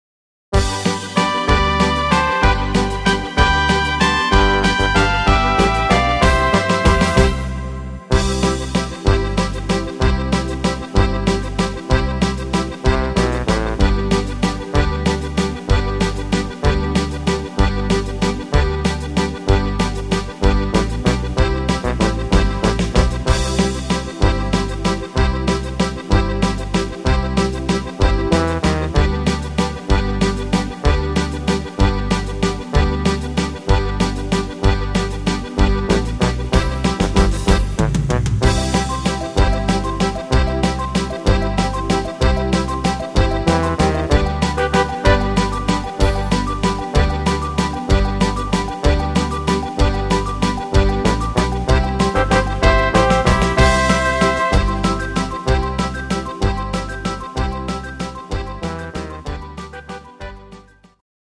Rhythmus  Waltz
Art  Fasching und Stimmung, Deutsch